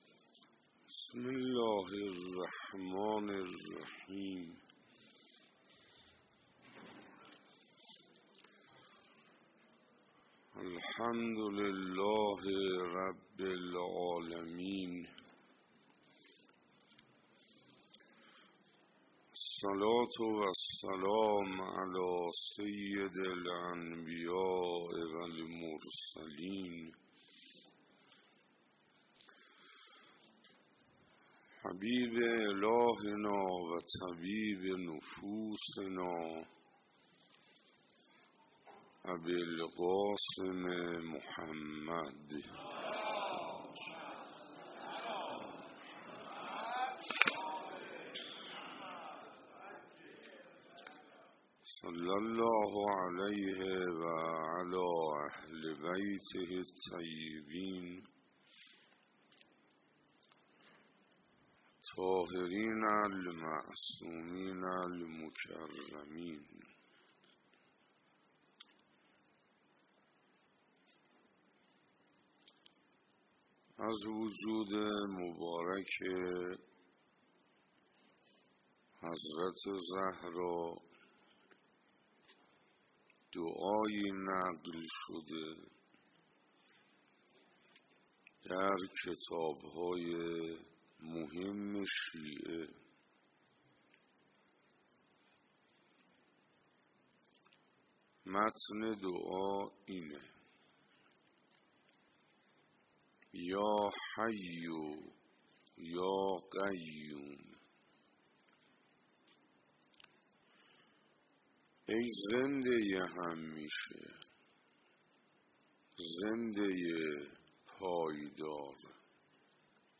کد خبر : ۱۲۱۱۹۹ عقیق: حجت‌الاسلام حسین انصاریان استاد اخلاق به مناسبت ایام فاطمیه طی سخنرانی خود در مسجد رسول اکرم(ص) تهران پیرامون موضوع پناه بندگان به لطف و کرم پروردگار اظهارداشت: در کتاب‌های مهم شیعه از وجود مبارک حضرت زهرا (س) دعایی نقل شده است که ایشان می‌فرمایند: «خداوندا!